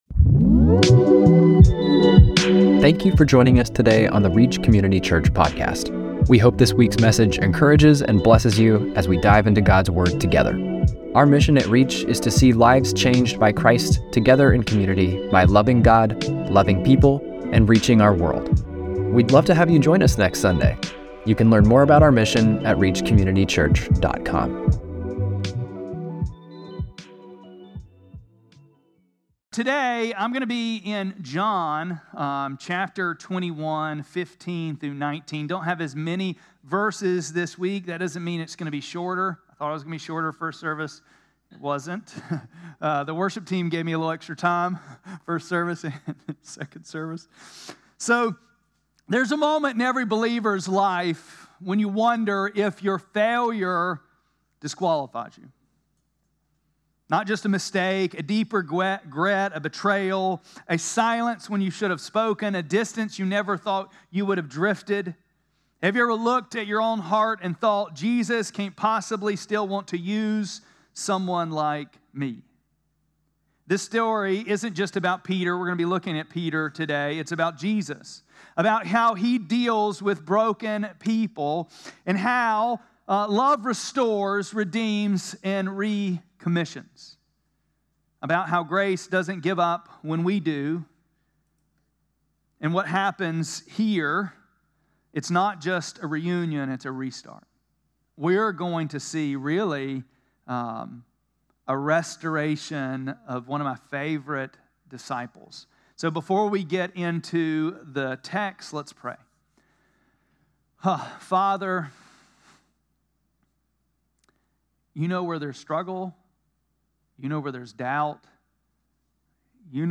6-8-25-Sermon.mp3